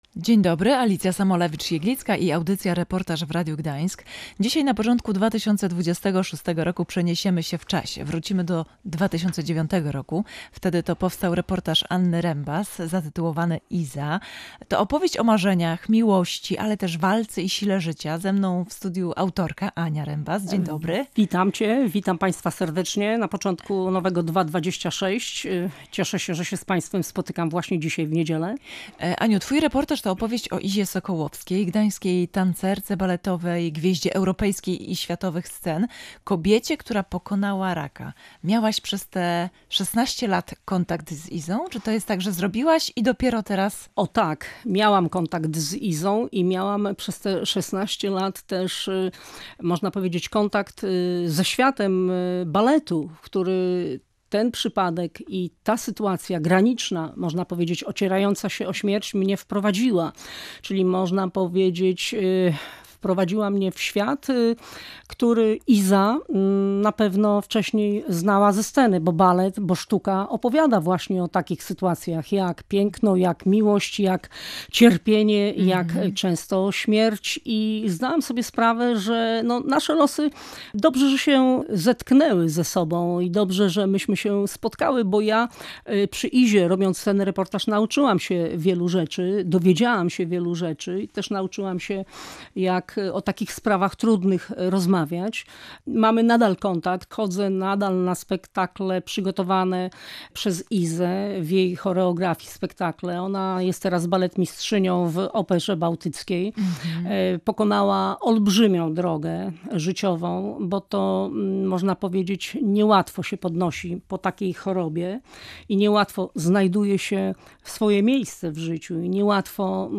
Wygrała z rakiem i wróciła do baletu. Dziś na pierwszym miejscu stawia rodzinę. Reportaż pt.